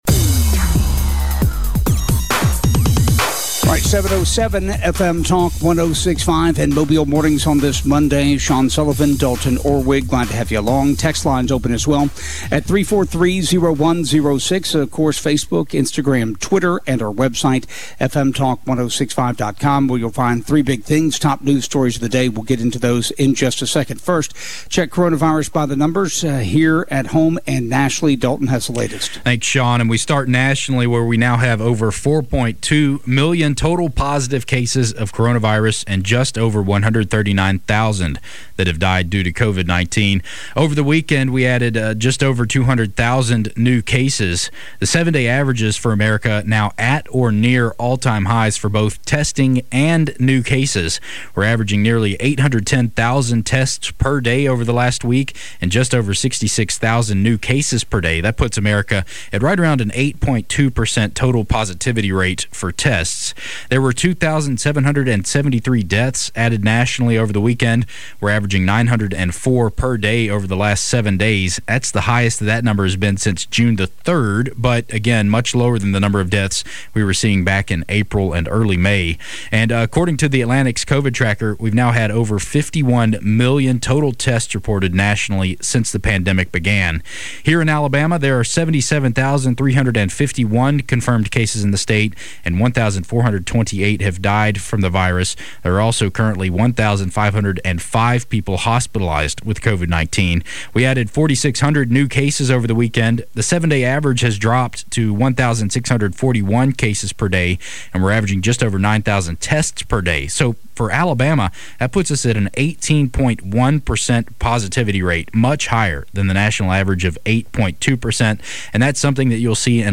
report on local news and sports
reports on traffic conditions. Other subjects include Covid 19 Stats, unemployment benefit, vaccines, mask rage.